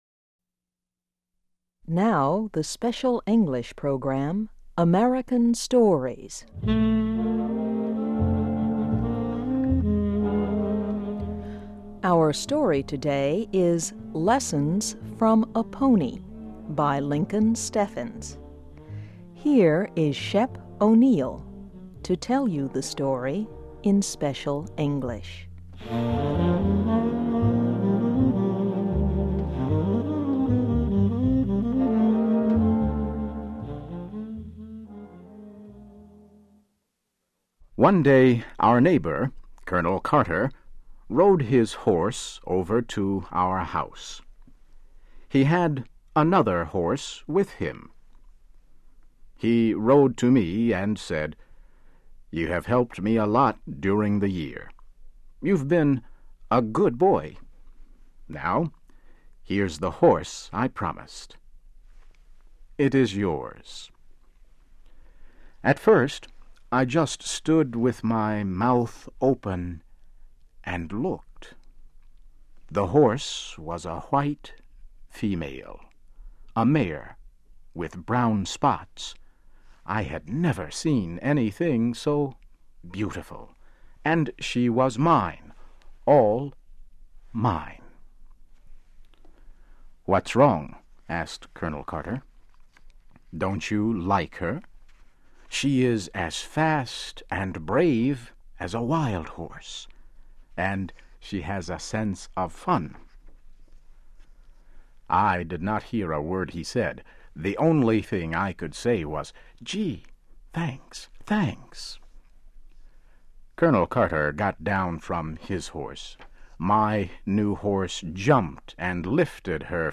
Learn English as you read and listen to a weekly show with short stories by famous American authors. Adaptations are written at the intermediate and upper-beginner level and are read one-third slower than regular VOA English.